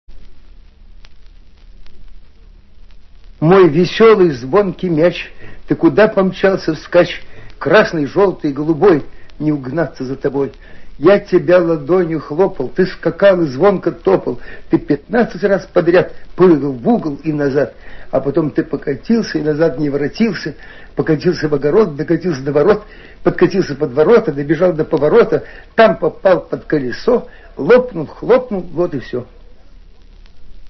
Marshak-Myach-chitaet-avtor-stih-club-ru.mp3